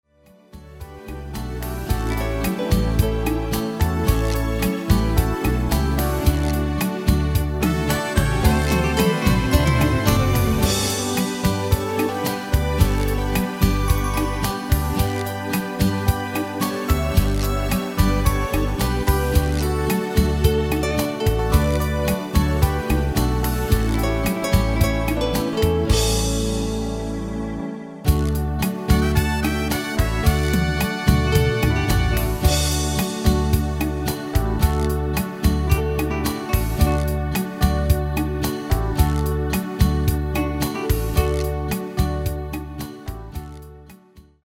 Basic MIDI File Euro 8.50
Demo's zijn eigen opnames van onze digitale arrangementen.